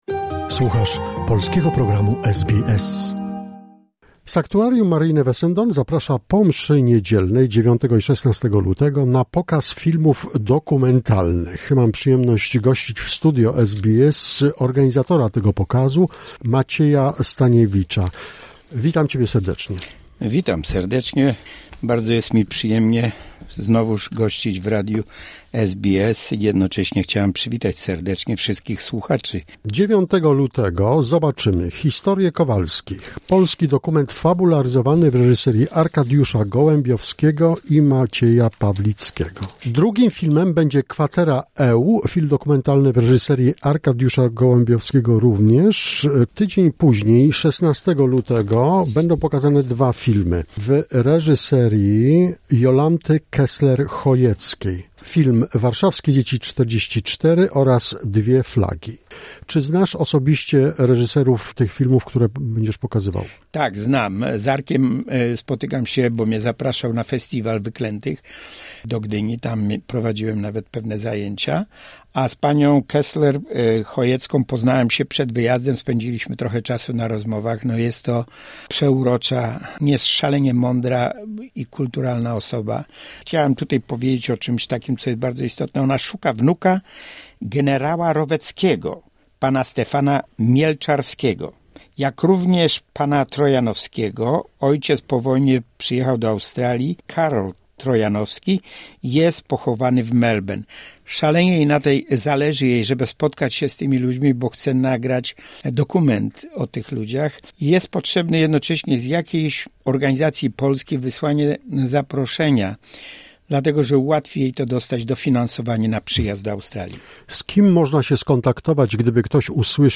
Ciąg dalszy rozmowy